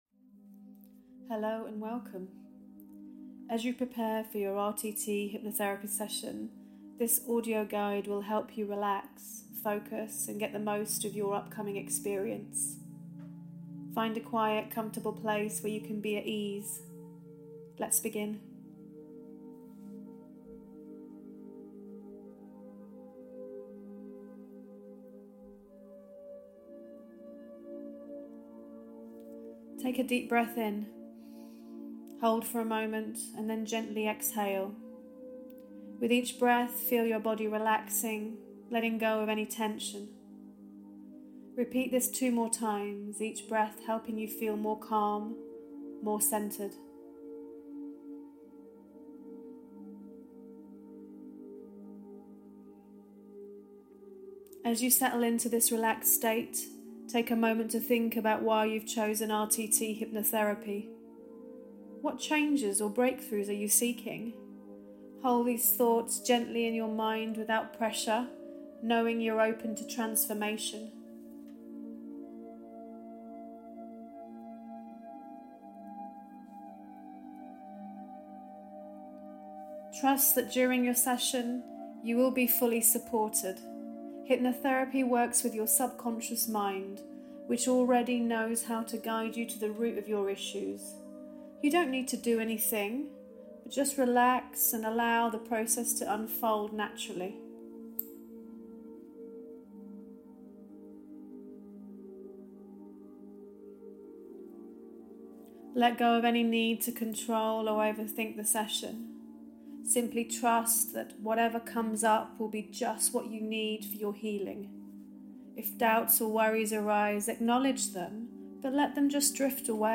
This soothing audio guide is designed to help you prepare mentally and emotionally for your RTT Hypnotherapy session.
With a calming backing track, the guide walks you through deep breathing exercises, setting positive intentions, and releasing any pre-session worries.